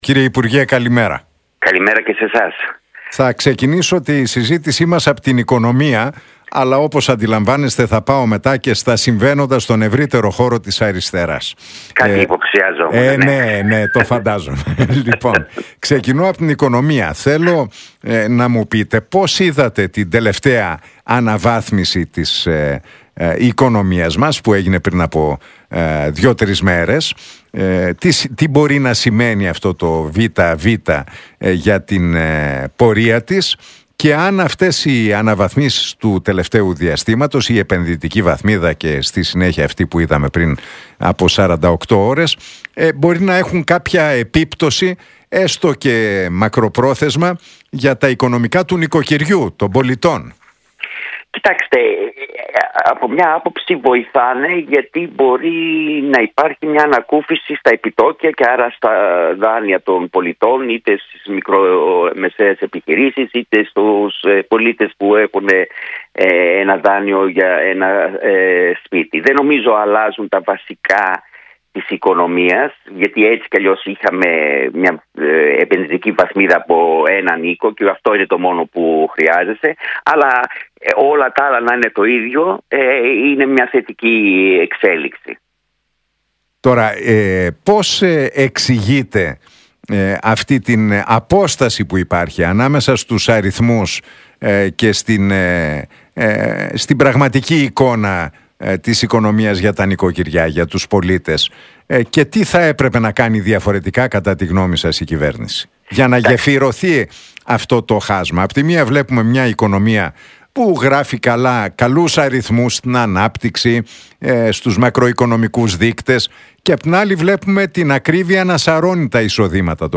Για την οικονομία, τις διεργασίες στην Αριστερά και την Κεντροαριστερά, τον Αλέξη Τσίπρα και τις εσωκομματικές διαφωνίες στην Νέα Αριστερά, μίλησε ο κοινοβουλευτικός εκπρόσωπος του κόμματος, Ευκλείδης Τσακαλώτος στον Νίκο Χατζηνικολάου από την συχνότητα του Realfm 97,8.